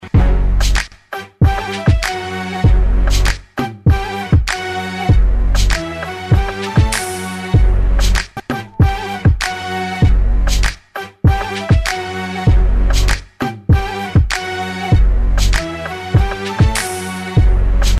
Рэп, Хип-Хоп, R'n'B [75]